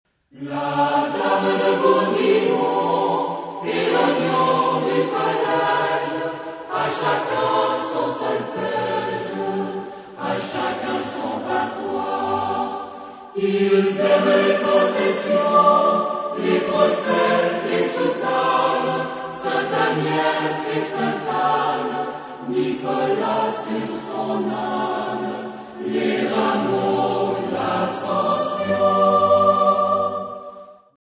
Genre-Style-Forme : Profane
Caractère de la pièce : joyeux ; humoristique
Type de choeur : SATB  (4 voix mixtes )
Tonalité : sol majeur